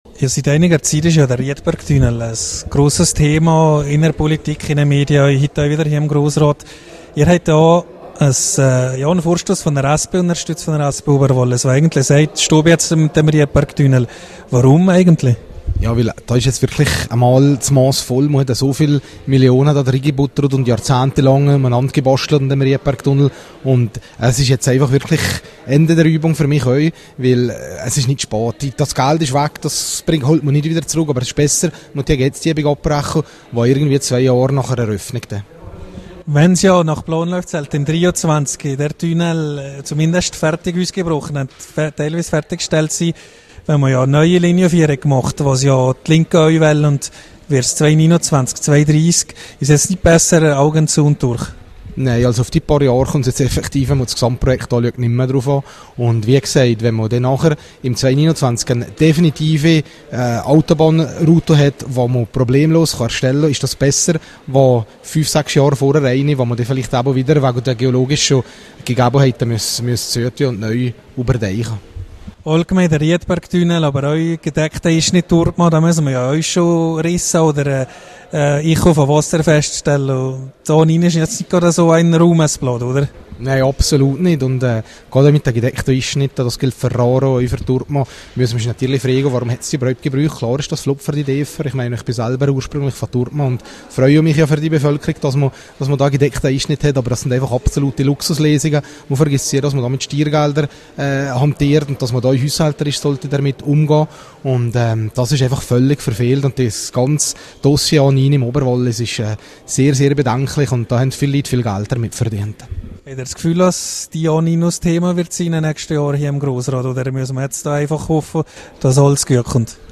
Rückblick auf den zweiten Tag der Märzsession mit SPO-Grossrat Gilbert Truffer.